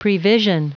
Prononciation du mot prevision en anglais (fichier audio)
Prononciation du mot : prevision